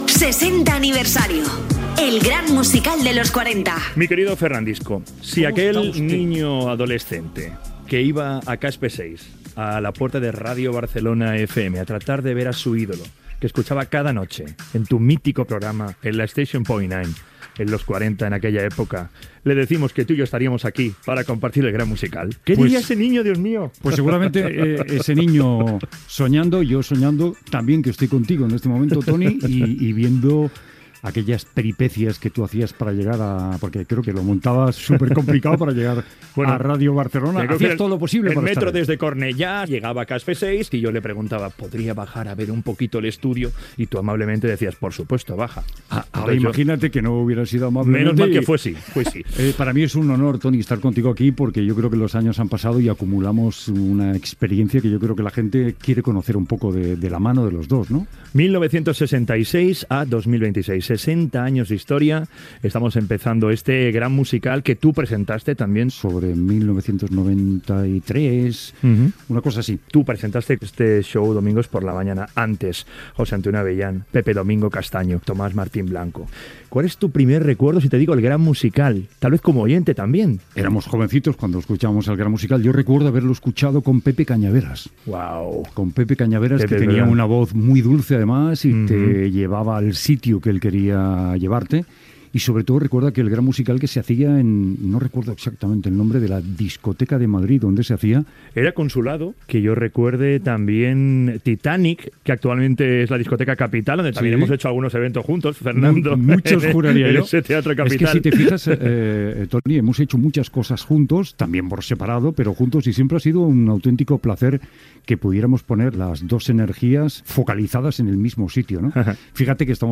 Indicatiu del programa, entrevista a Fernando Martínez "Fernandisco", recordant la seva trajectòria a Cadena 40 Principales i "El Gran Musical".
Musical
FM